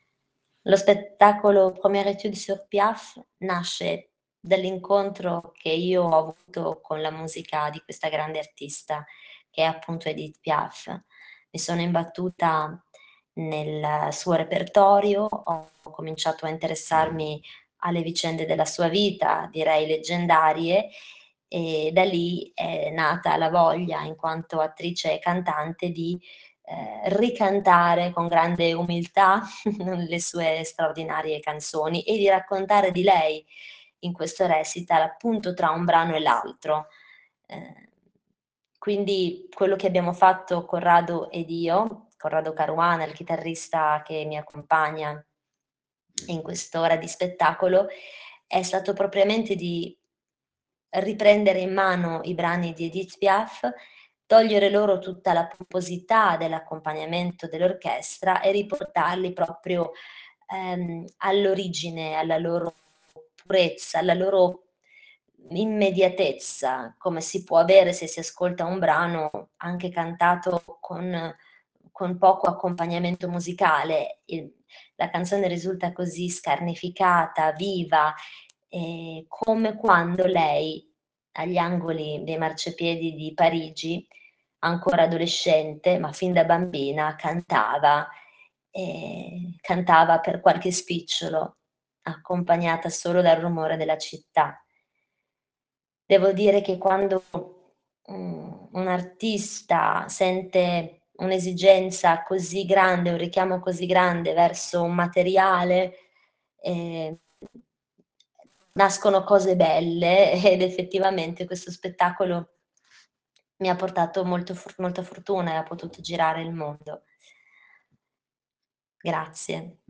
voce
chitarra manouche
e accompagnati dalla freschezza di arrangiamenti originali in stile swing manouche. ho ricantato le sue canzoni senza nessun tentativo d’imitazione